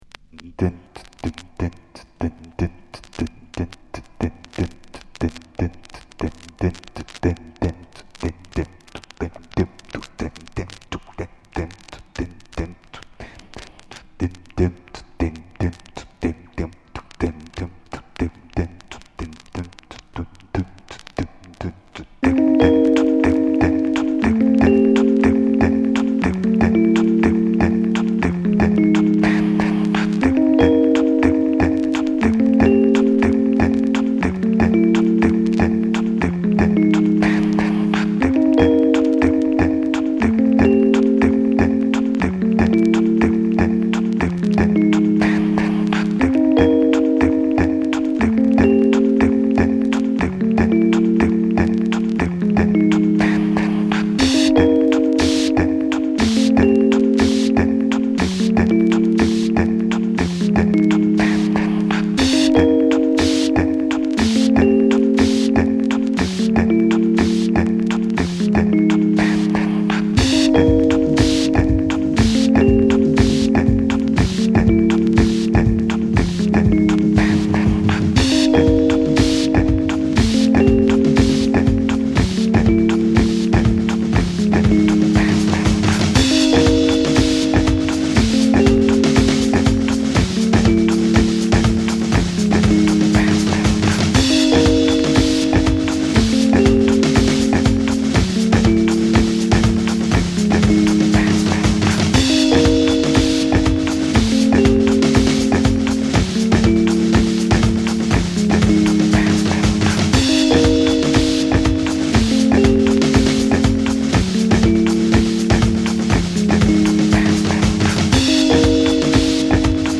極めて前衛的でエクスペリメンタルな5トラック。